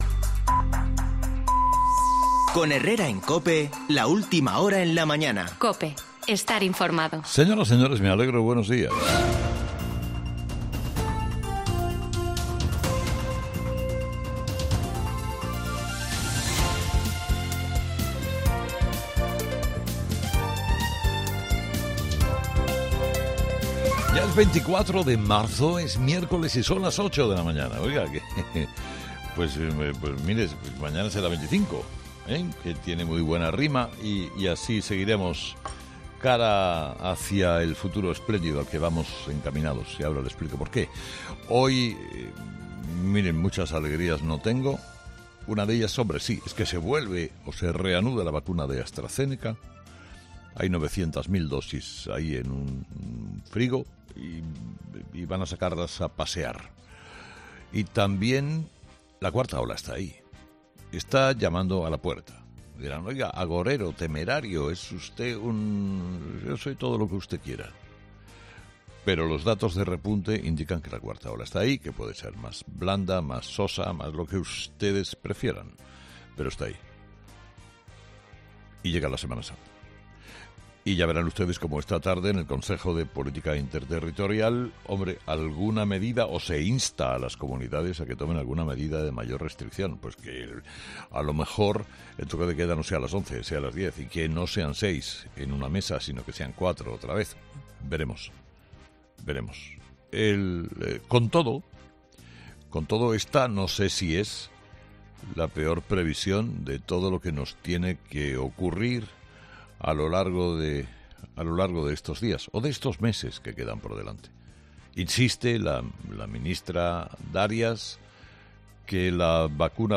ESCUCHA AQUÍ EL MONÓLOGO DE HERERRA